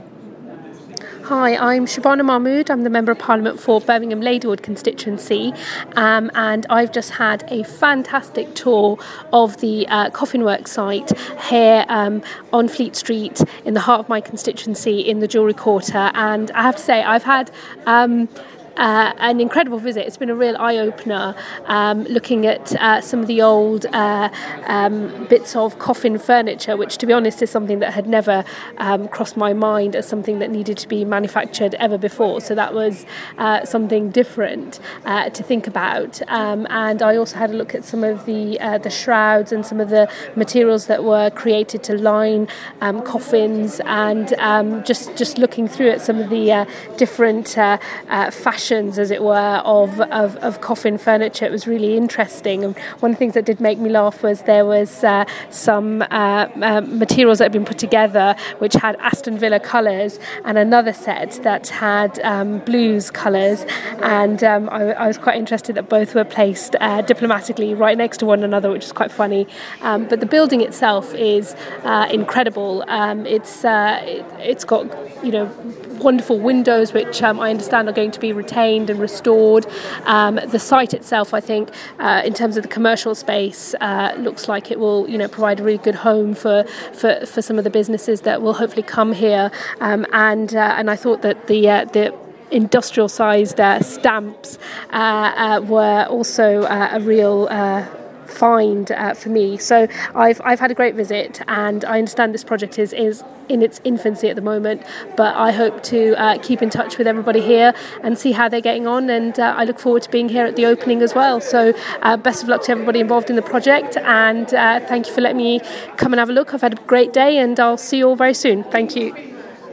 Shabana Mahmood MP for Aston, Ladywood, Nechells and Soho speaks following a visit to Birmingham Conservation Trusts Newman Brothers Coffin Works.